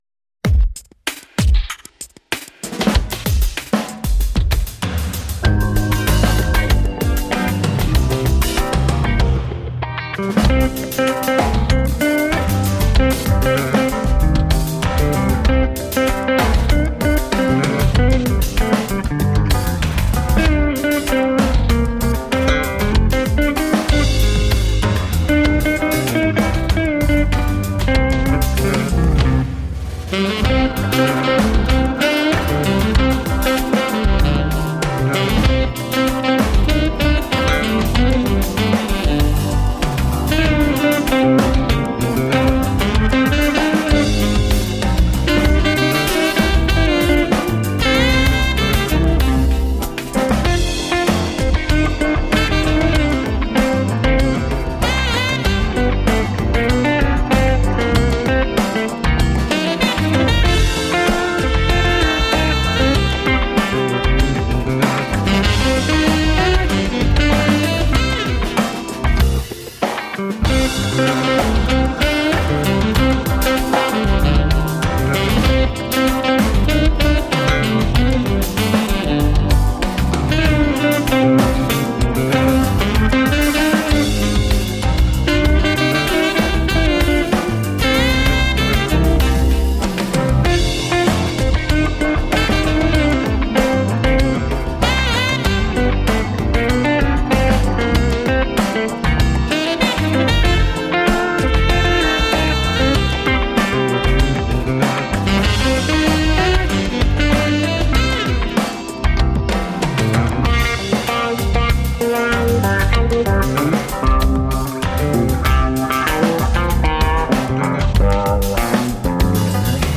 Groovy smooth jazz to brighten your day.